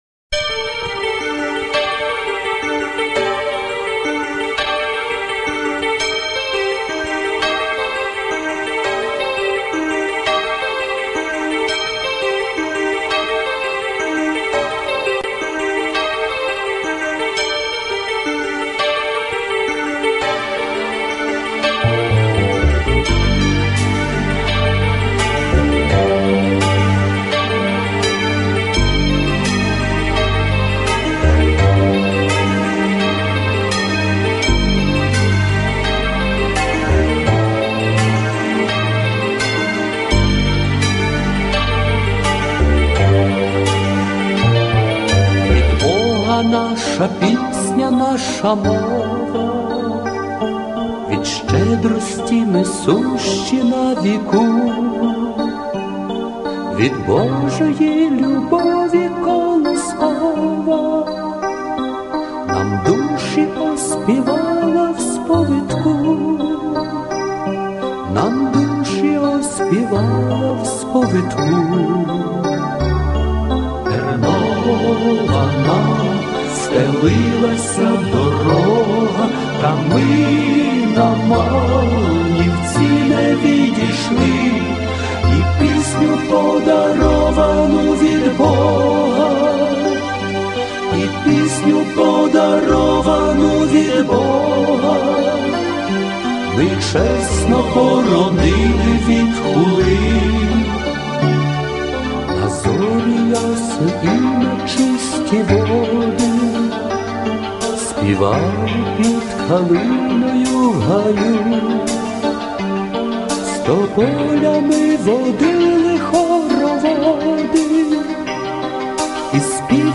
Помірна
Соло
Чоловіча